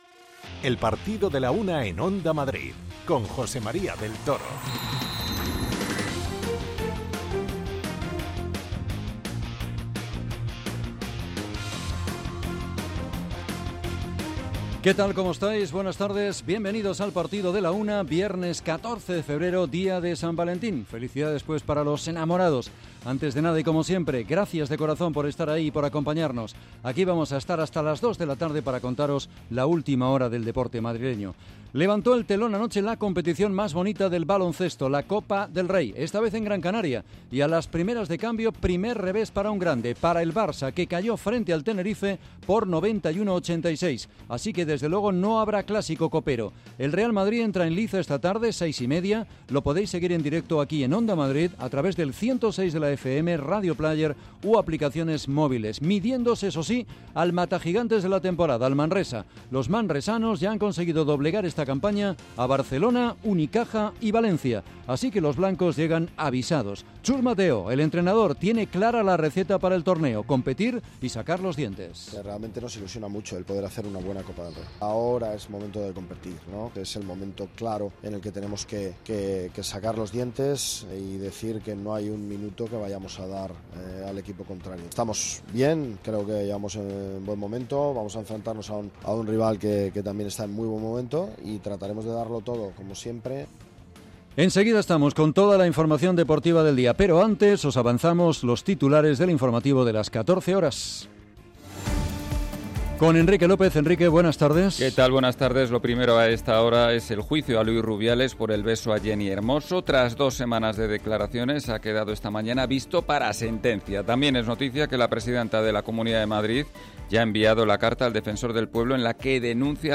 Escuchamos la rueda de prensa de Simeone analizando el momento del equipo y el partido.
Escuchamos las reflexiones de Vicente Moreno, el entrenador de los navarros.